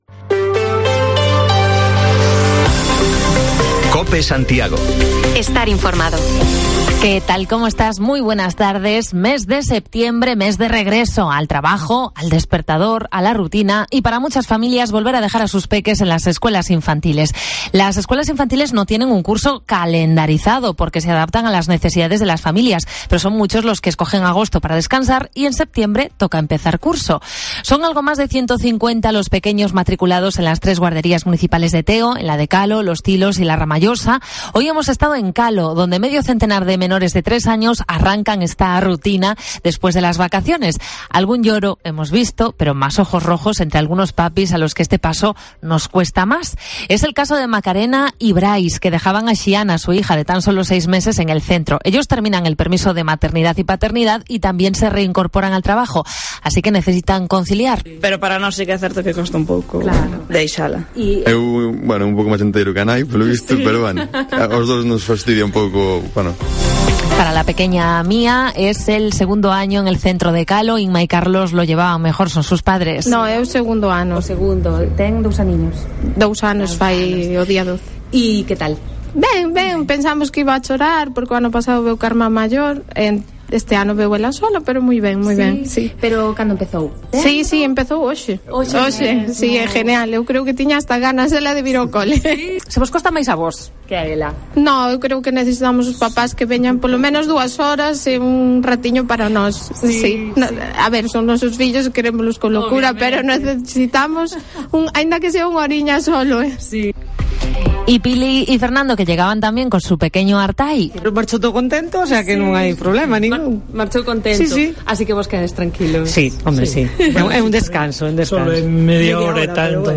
Primer lunes de septiembre que vivimos desde la escuela infantil de Calo, en Teo: recogemos testimonios de papis y peques que hoy comienzan una etapa con ilusión y algún llanto.